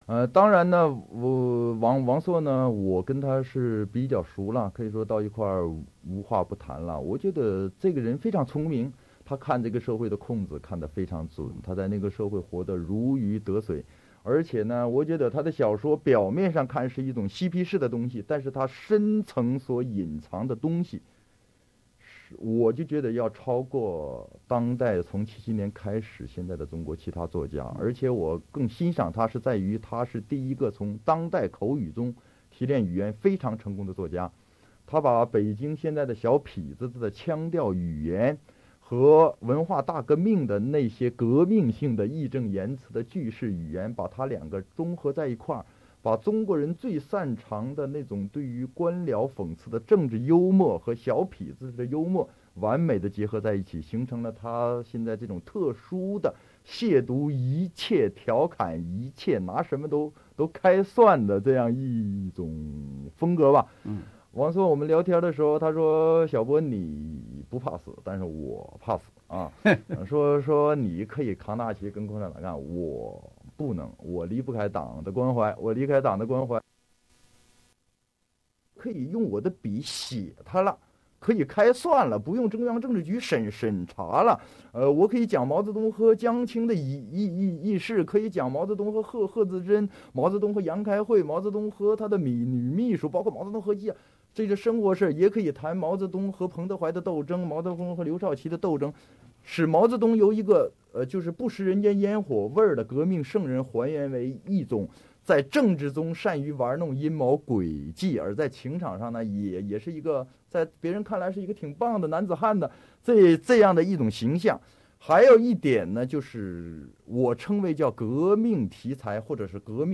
这个录音是1993年刘晓波在澳大利亚做访问学者期间制作的。24年前的这份电台采访录音依然非常清晰。
在被主持人问及作家王朔是否也属于他所谈到的这类文化现象时，刘晓波笑着回答，王朔是他的好朋友，哥们。